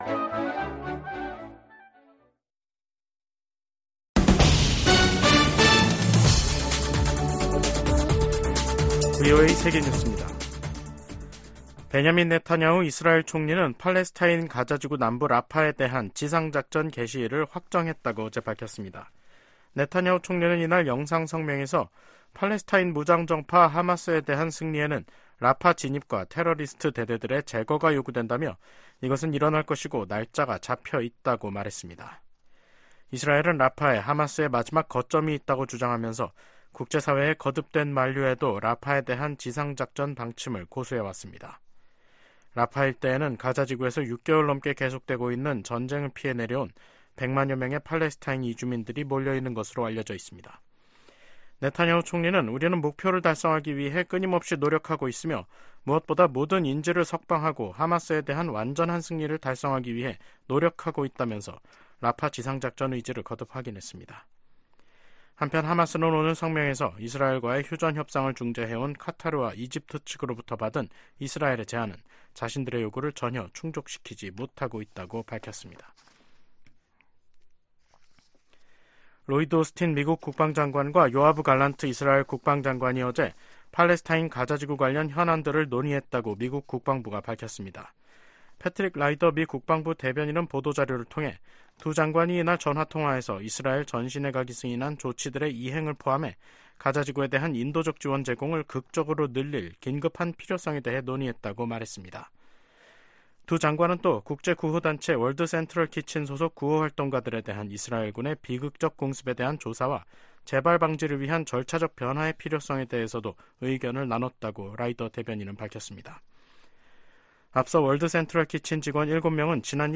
세계 뉴스와 함께 미국의 모든 것을 소개하는 '생방송 여기는 워싱턴입니다', 2024년 4월 9일 저녁 방송입니다.